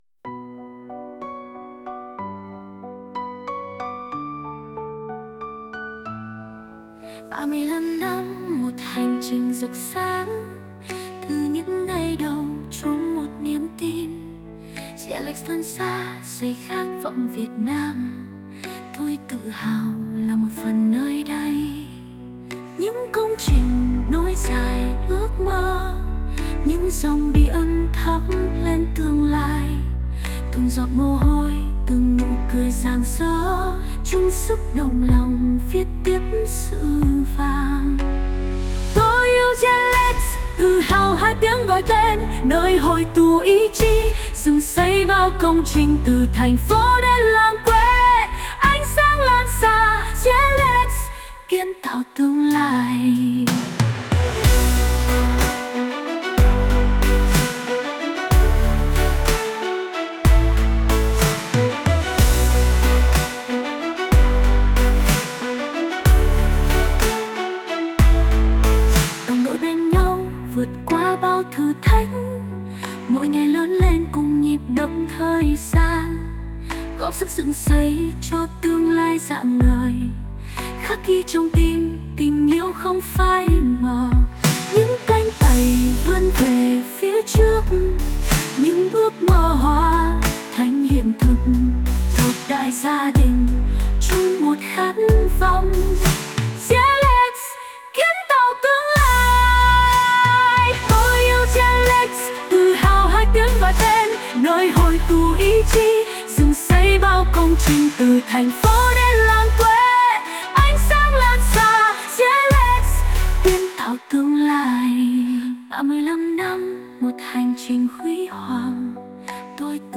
Tôi yêu GELEX (Nhạc AI